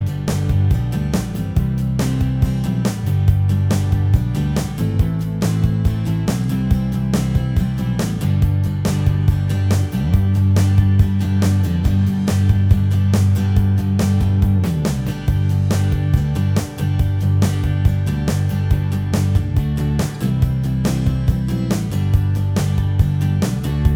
Minus Electric Guitar Soft Rock 3:30 Buy £1.50